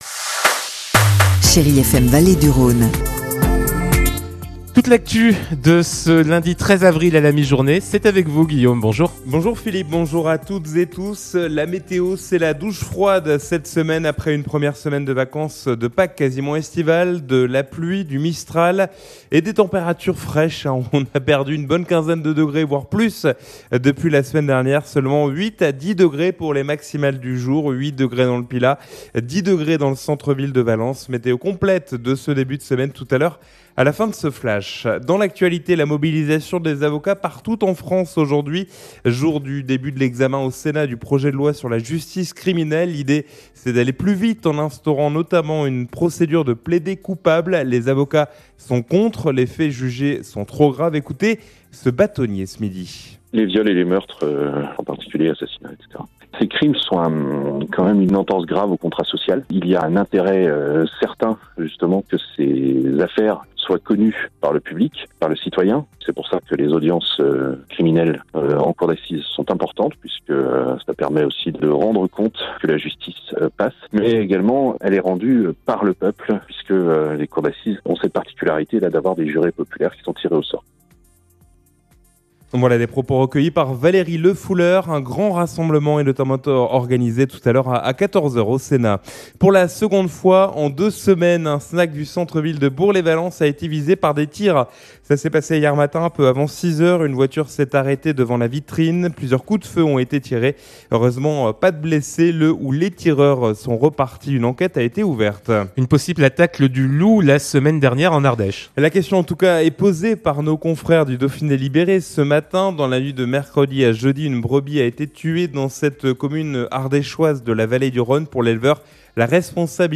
in Journal du Jour - Flash
Lundi 13 avril : Le journal de 12h